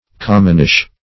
Commonish \Com"mon*ish\, a. Somewhat common; commonplace; vulgar.
commonish.mp3